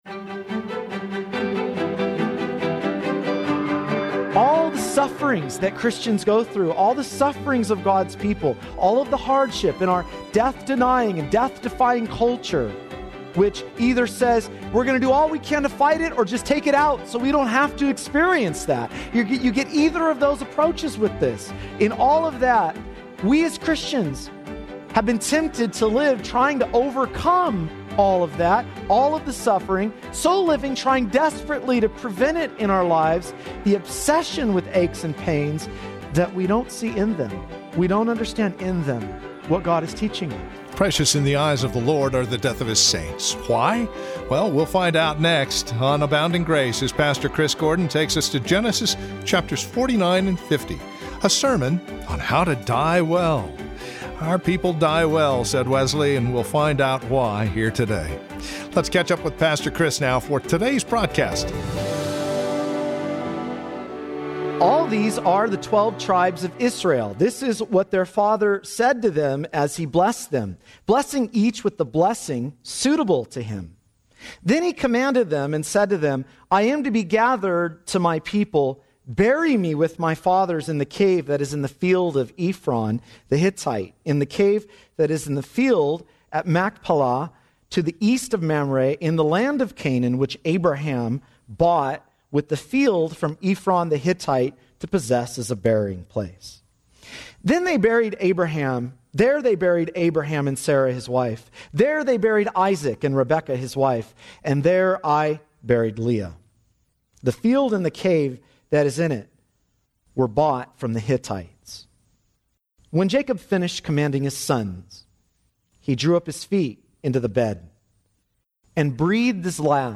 A Sermon On How To Die Well - Part 1
a_sermon_on_how_to_die_well_part_1_dfslm0s3y2.mp3